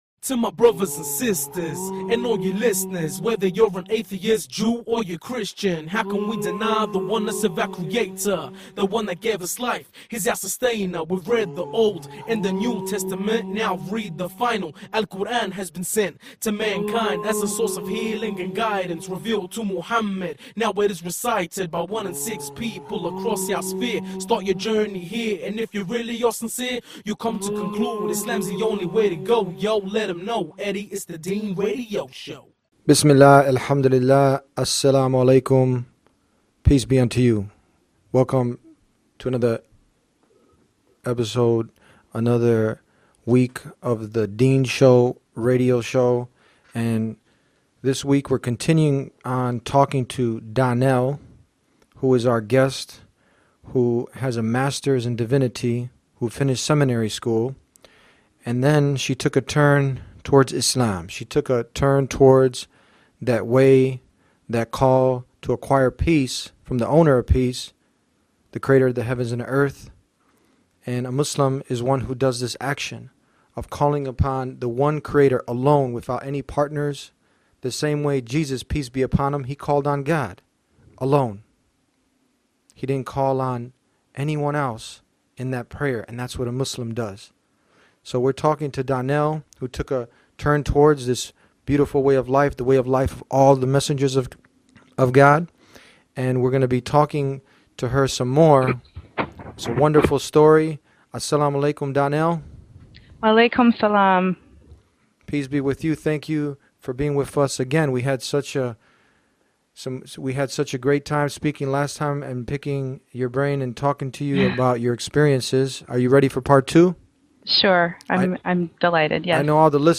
All we can say is you don’t want to pass this interview up.